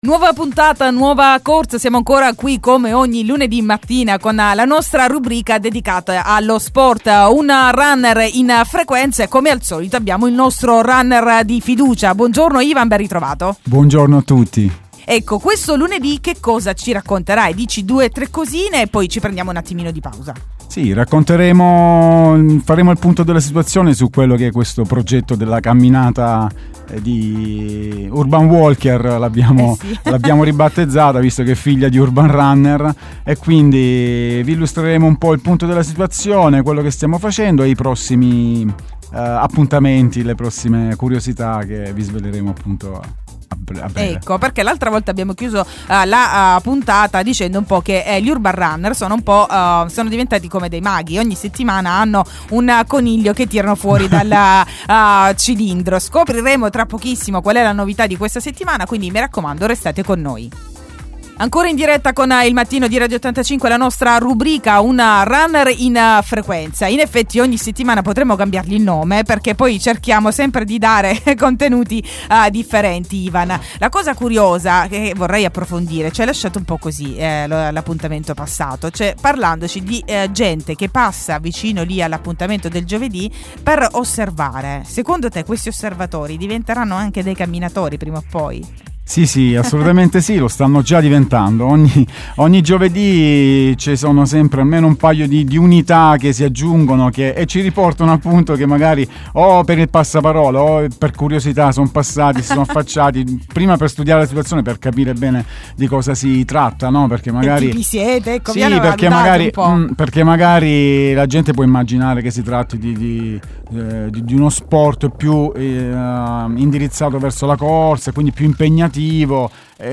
OGNI LUNEDI’, A PARTIRE DALLE 10.00, IN DIRETTA SULLE NOSTRE FREQUENZE SI PARLA DI SPORT E DEI SUOI BENEFICI CON GLI AMICI DI URBAN RUNNER